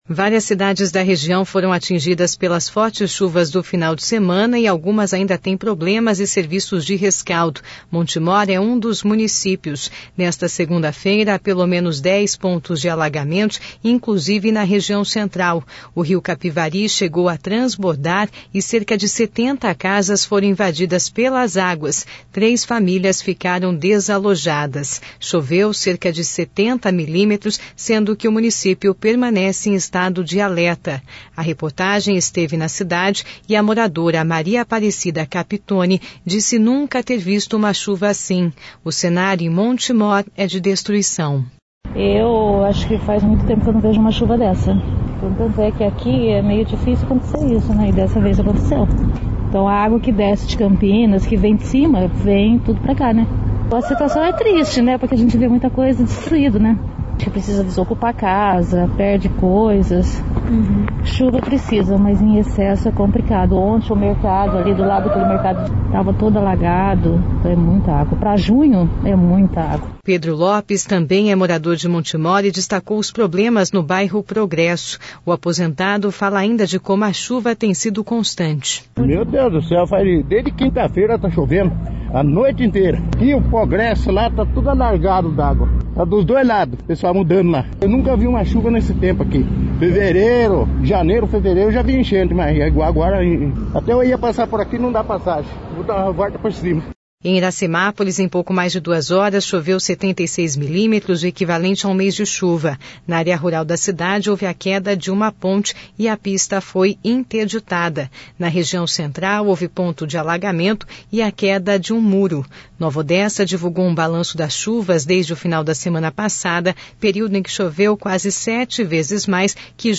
A reportagem esteve nessa manhã na cidade e a moradora